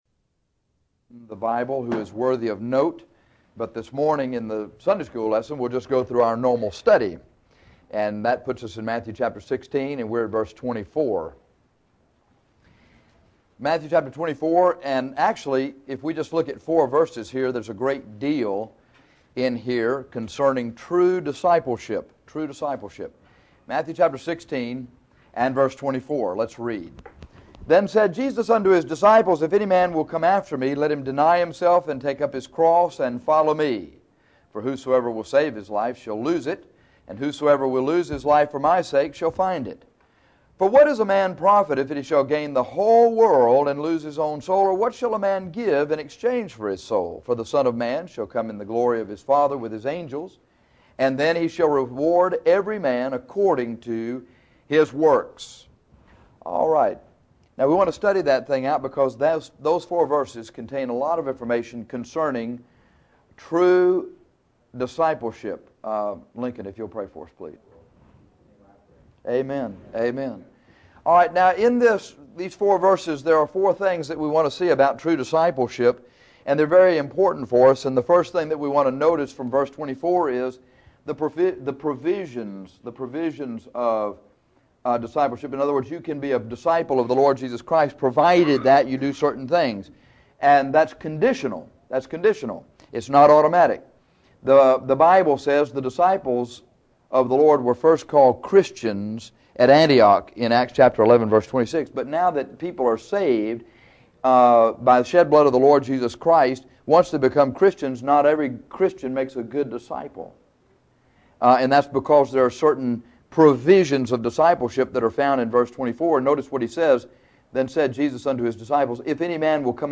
True discipleship involves the cross, a loss, and self-denial, but it also has a wonderful payoff. This lesson should encourage you.